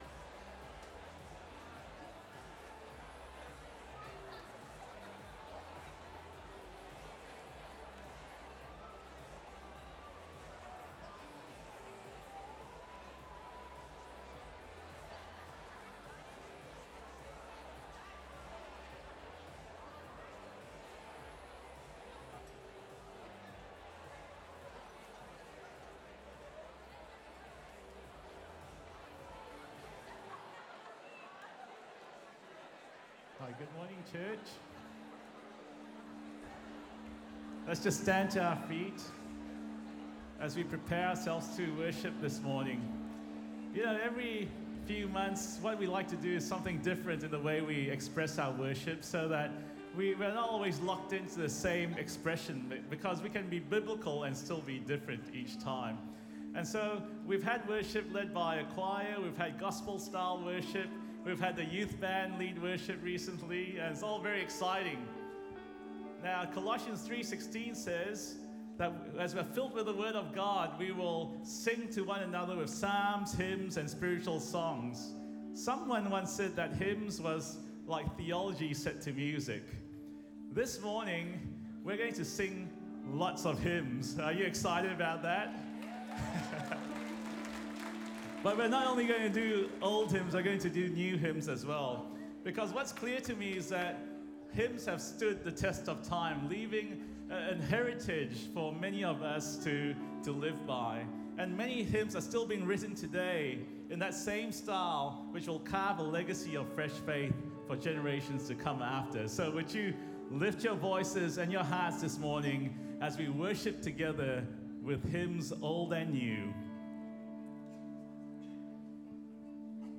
hymns-sunday-2019.mp3